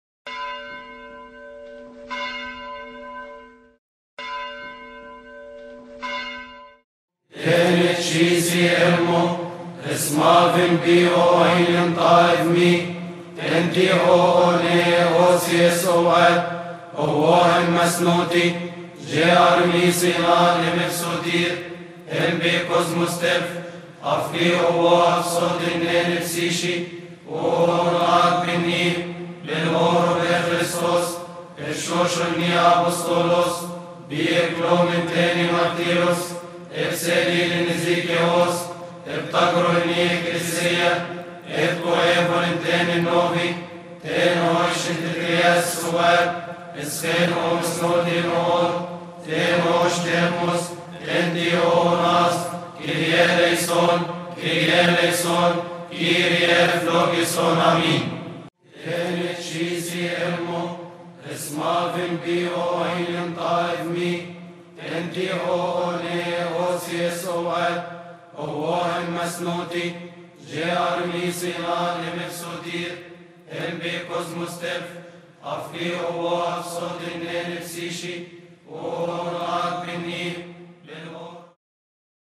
مقدمة قانون الإيمان لخورس الكلية الإكليريكية اللاهوتية بدير المحرق، قبطي.
المصدر: خورس الكلية الإكليريكية اللاهوتية بدير المحرق